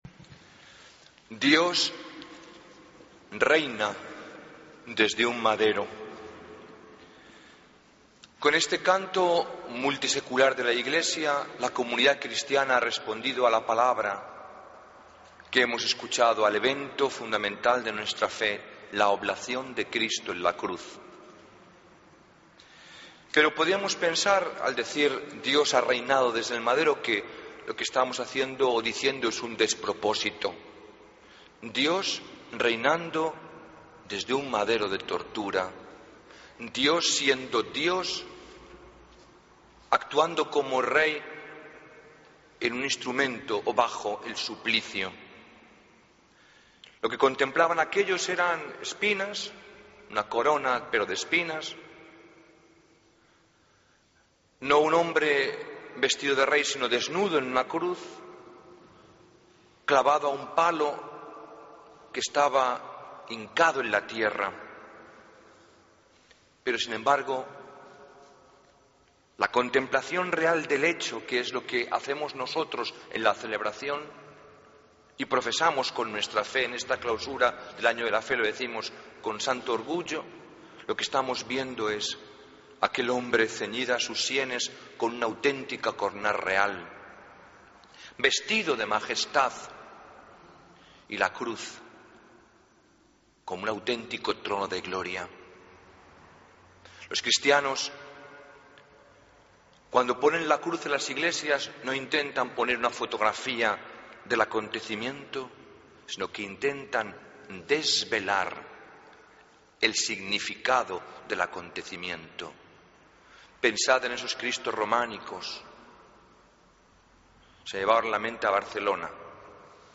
Homilía del Domingo 24 de Noviembre de 2013